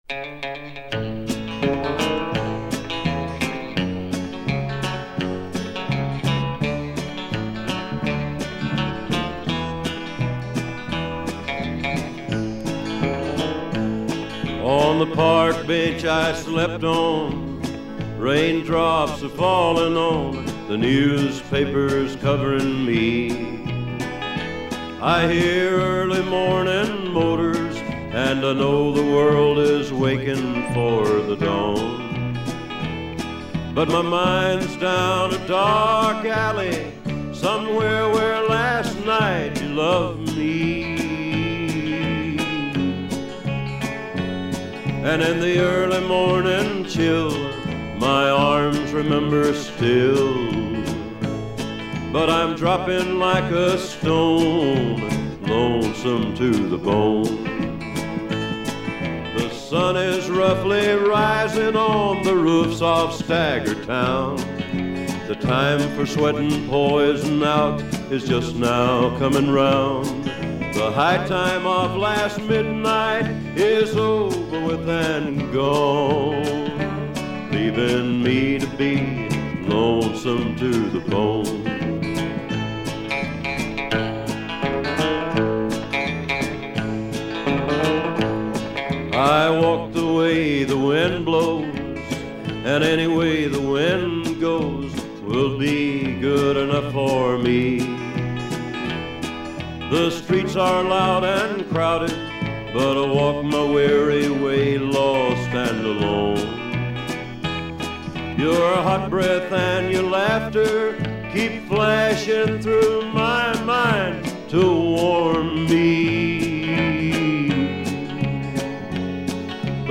Жанр: Folk, World, & Country